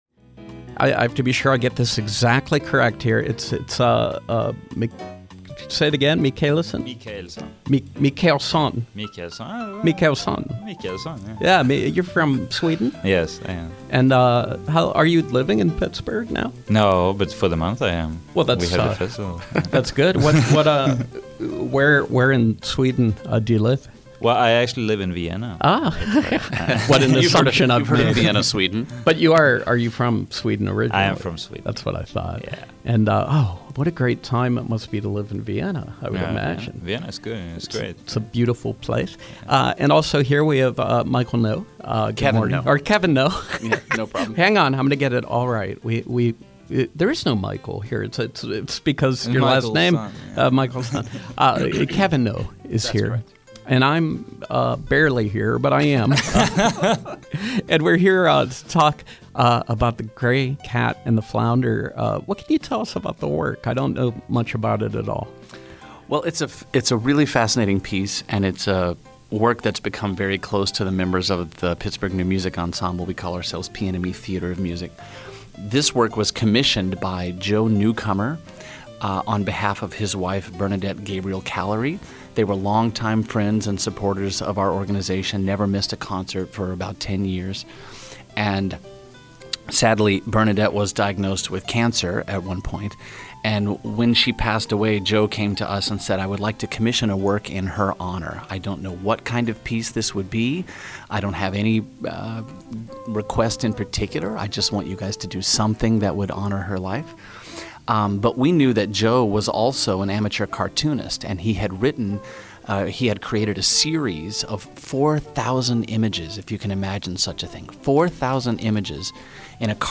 In-Studio Pop Up: Pittsburgh New Music Ensemble